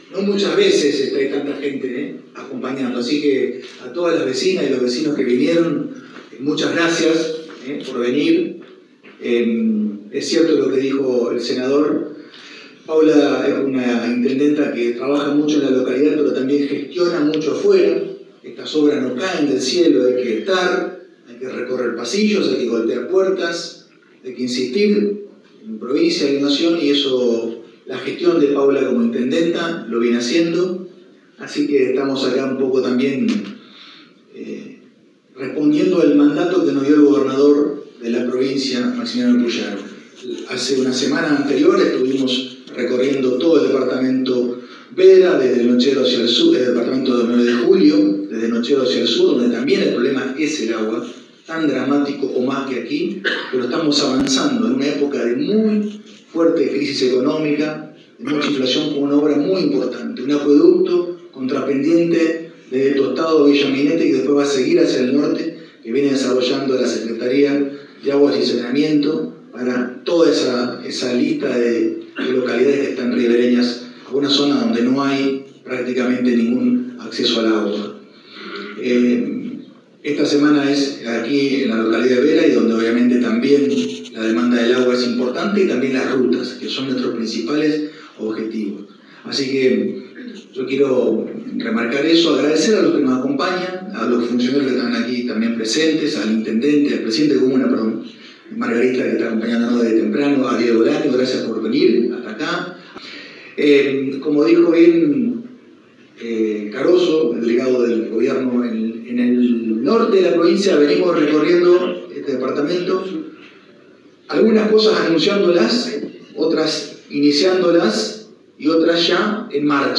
Ministro de Obras Públicas del gobierno de Santa Fe – Lisando Enrico